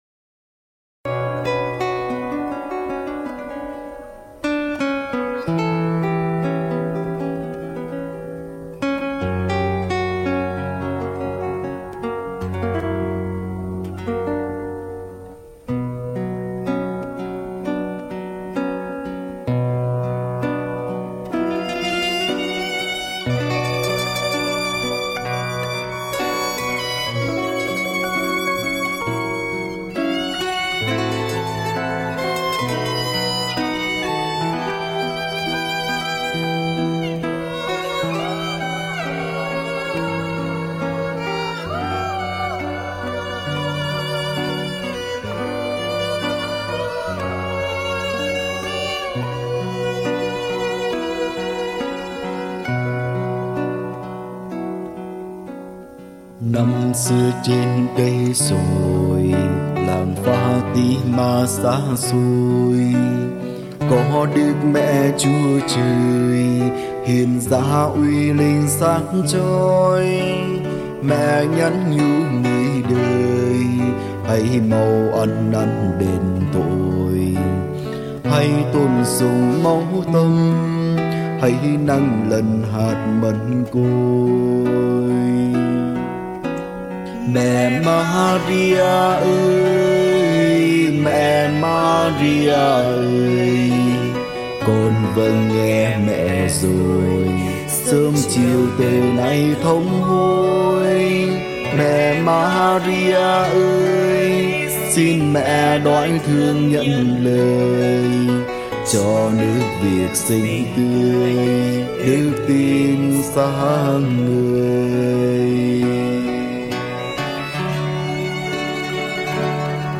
Thể loại: Đức Mẹ